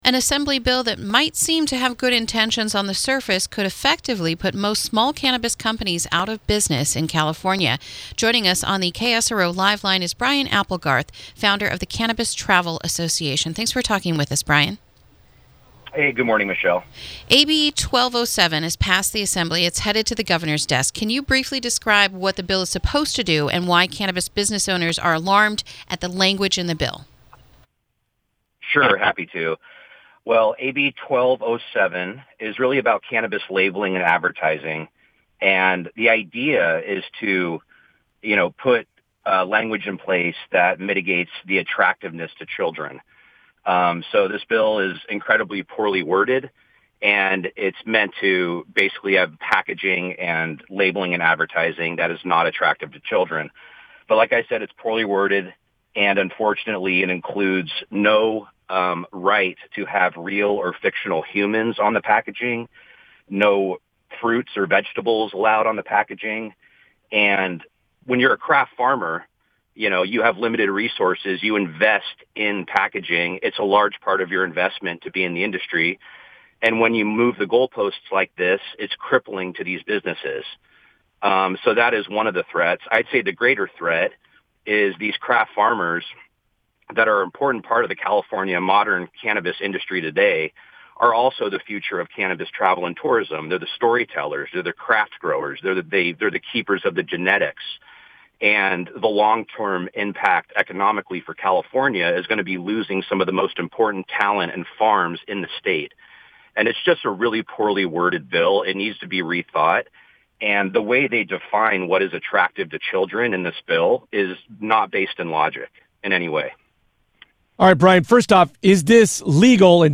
INTERVIEW: New Cannabis Legislation May Eradicate Small Businesses